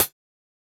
Perc (6).wav